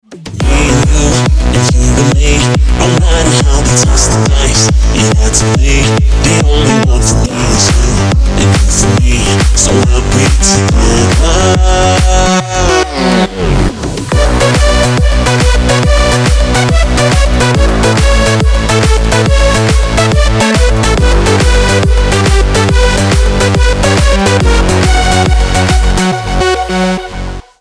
• Качество: 320, Stereo
громкие
Стиль: big room